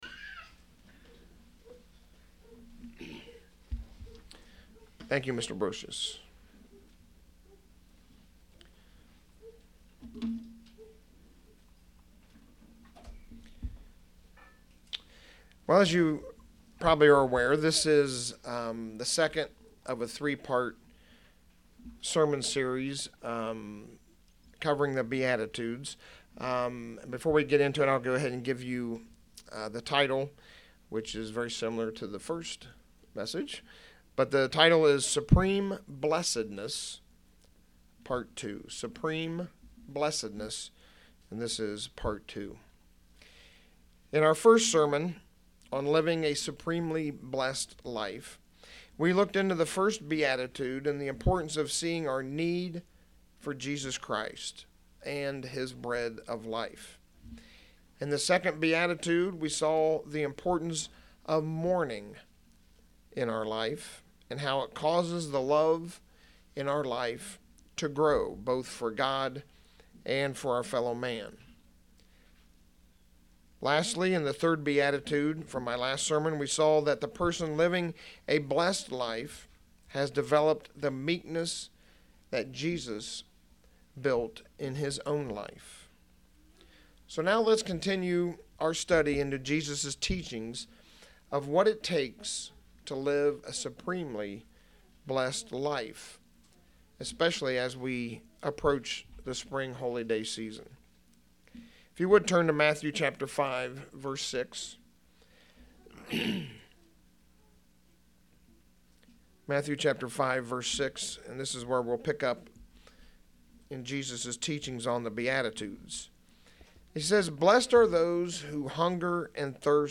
Sermons
Given in York, PA